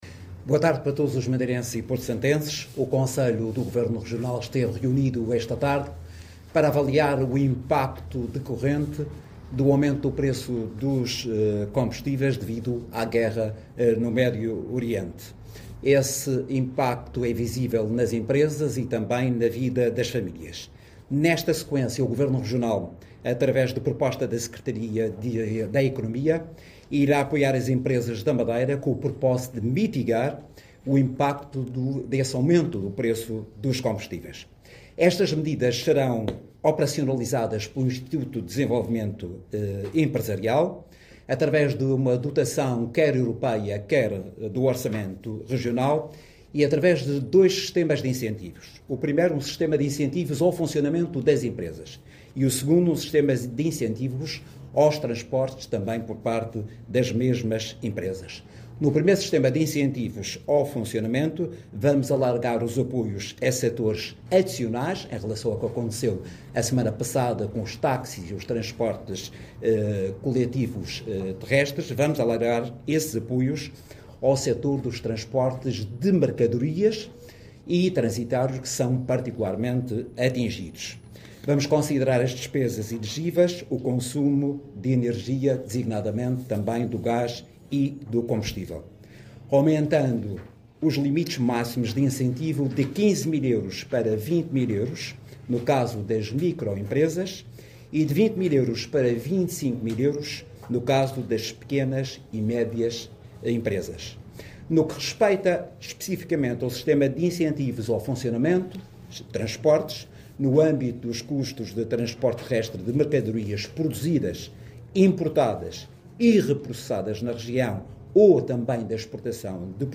O Governo Regional da Madeira, através da Secretaria Regional de Economia, anunciou, hoje, um conjunto de medidas no valor de 9 milhões de euros para ajudar as empresas da Região a enfrentar o aumento dos preços dos combustíveis, provocado pela guerra no Médio Oriente. A iniciativa, coordenada pela Secretaria Regional de Economia, será operacionalizada pelo IDE – Instituto de Desenvolvimento Empresarial, através da dotação RUP do programa Madeira 2030, clarificou o Secretário Regional em conferência de imprensa.